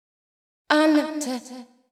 House / Voice / VOICEGRL167_HOUSE_125_A_SC2.wav